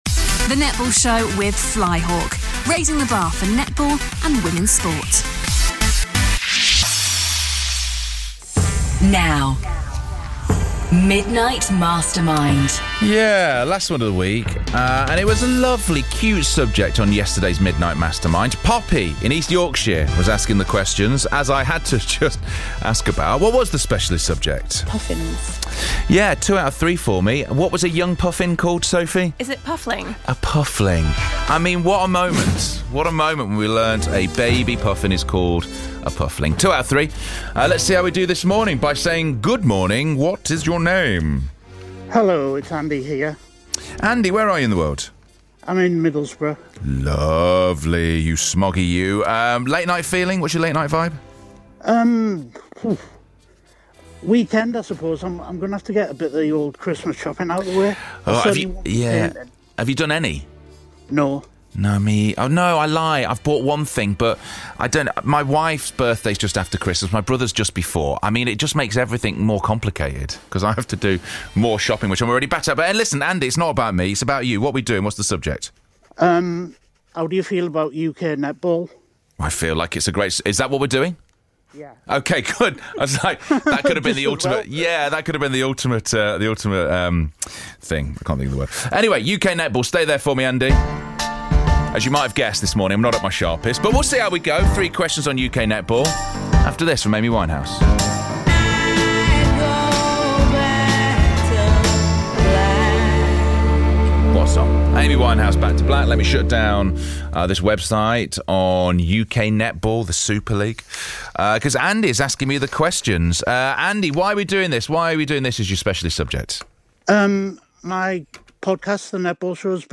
We were delighted to be asked to take part in OJ Borg's Midnight Mastermind competition on BBC Radio 2 no less